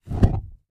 FlameSuctionCan HI028201
Flame Suction In Can